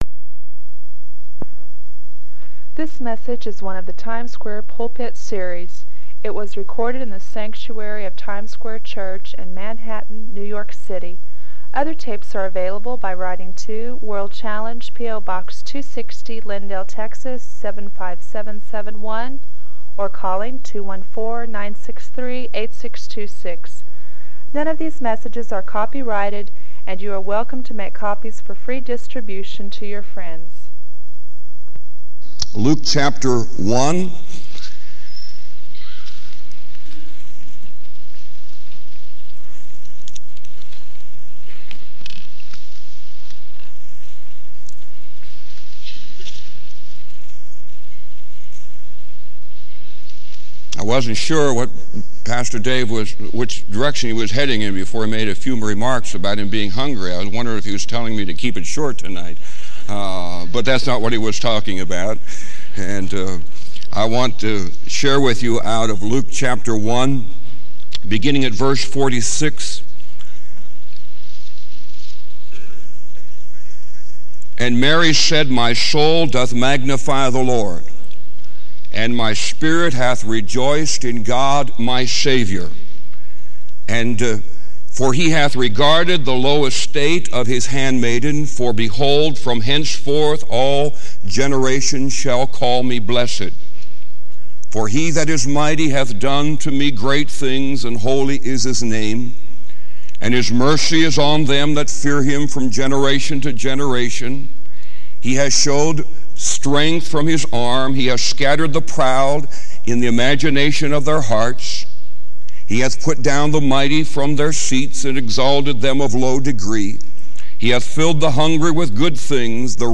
This sermon encourages listeners to embrace their spiritual position and rest in the assurance of Christ's sacrifice and judgment.